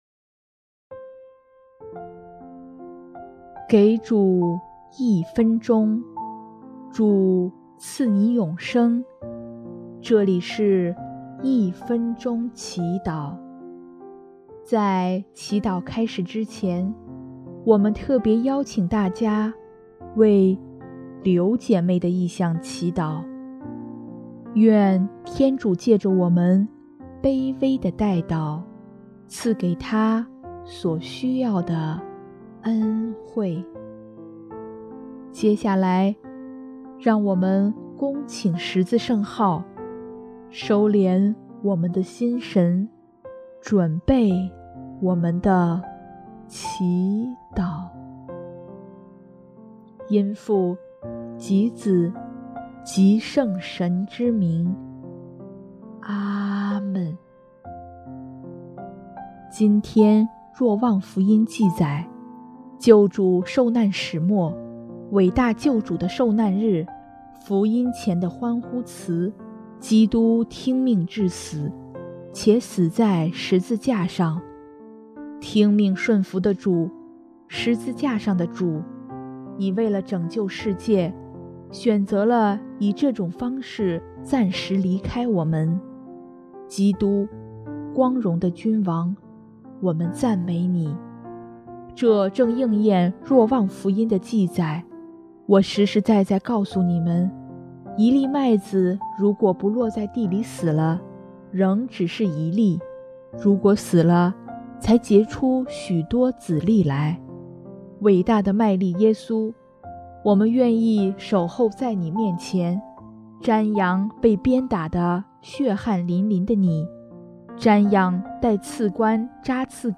音乐：主日赞歌《光荣你的名》